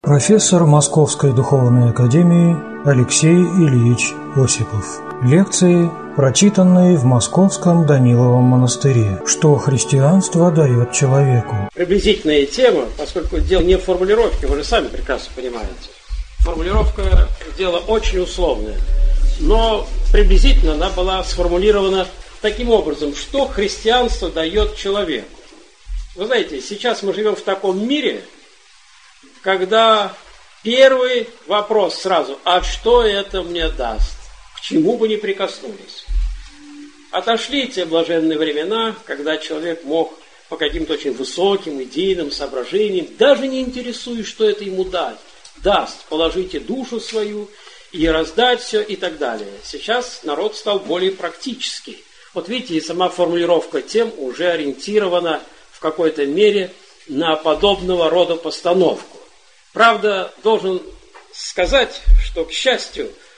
Аудиокнига Что Христианство дает человеку | Библиотека аудиокниг